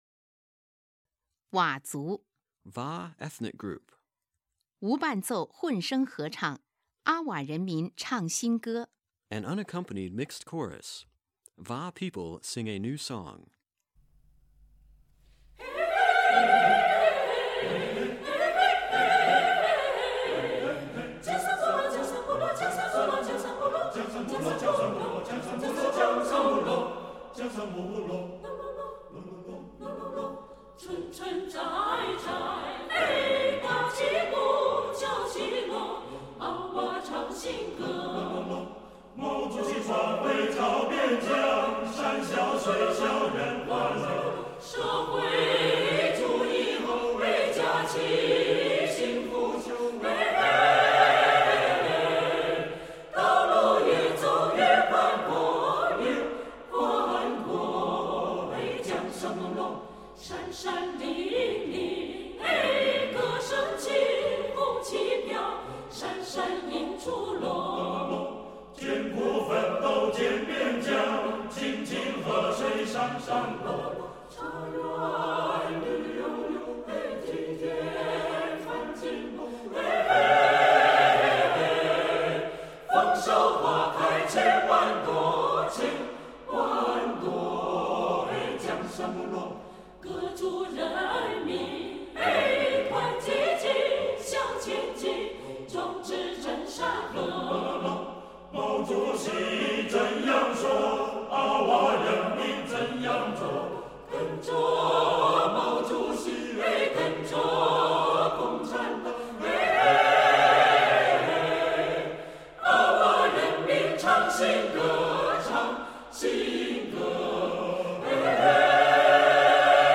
无伴奏混声合唱